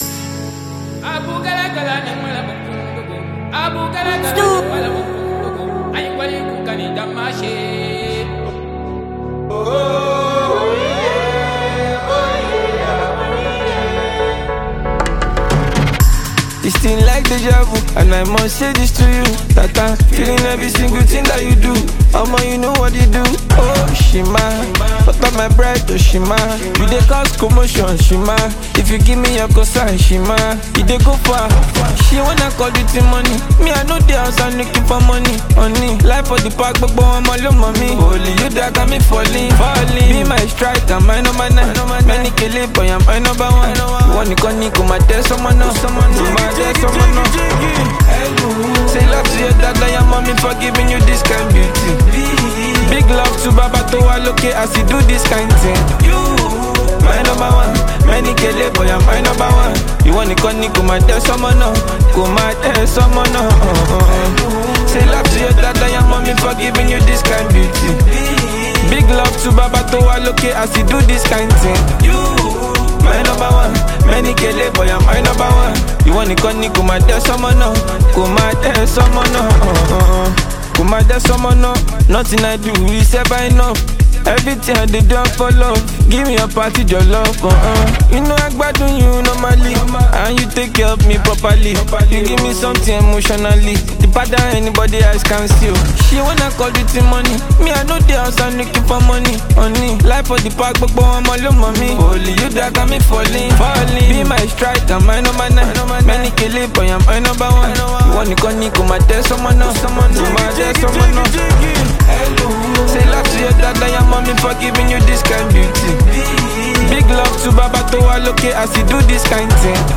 is an upbeat, danceable track